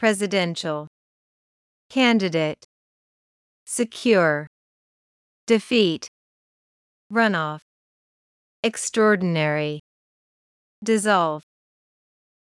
音声を再生し、強勢のある母音（＝大きな赤文字）を意識しながら次の手順で練習しましょう。